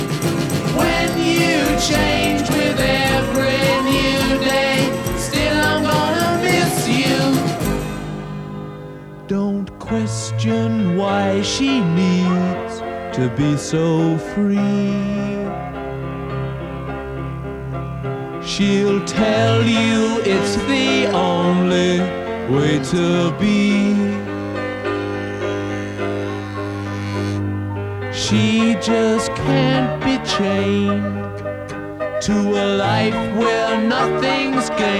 # Рок